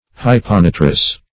Hyponitrous \Hy`po*ni"trous\, a. [Pref. hypo- + nitrous.]